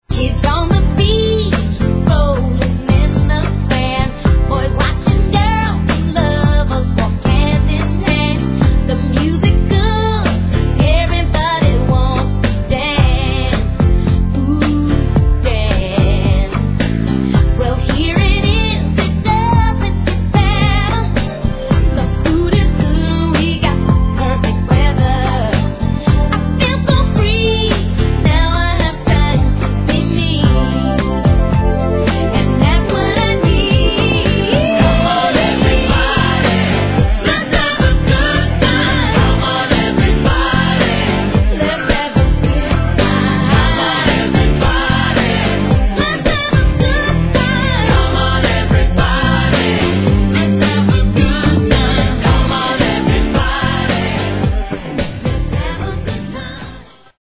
Summer-Hit Feeling